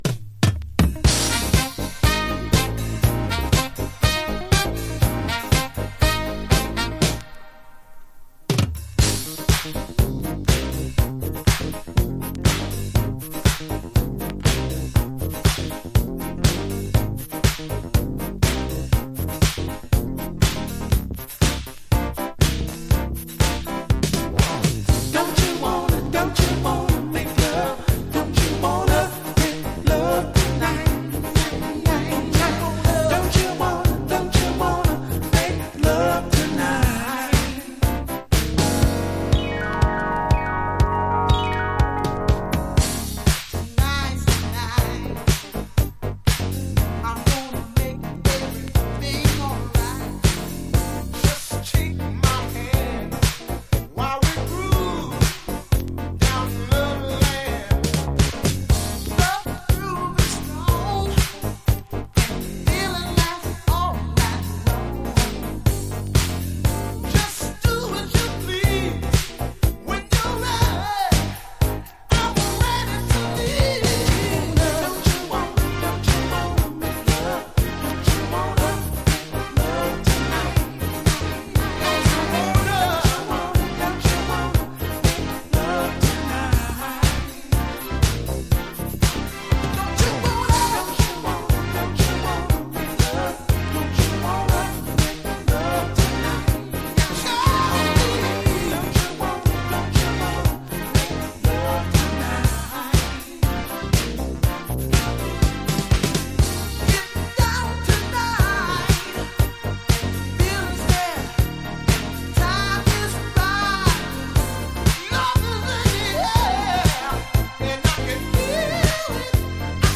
ロマンティックでメロウなファンキー・ディスコ・チューン!!
# FUNK / DEEP FUNK# DISCO